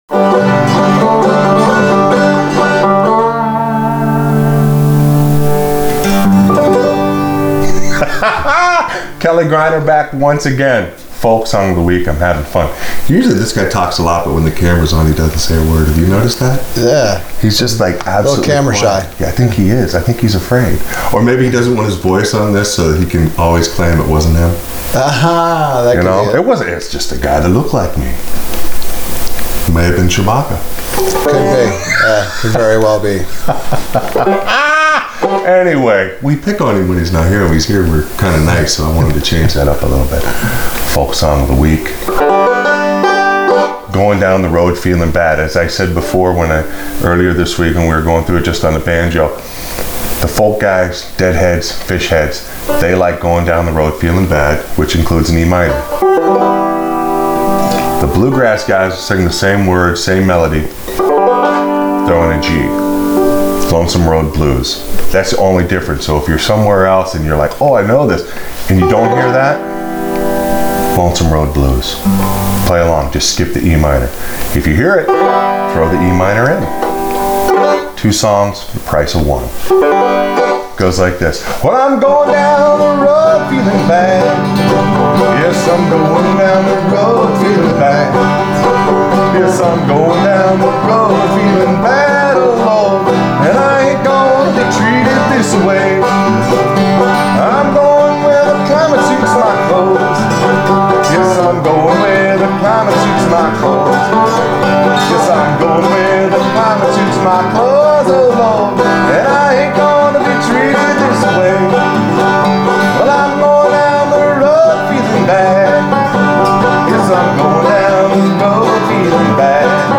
Folk Song Of The Week – Going Down The Road Feeling Bad – Accompaniment for Frailing Banjo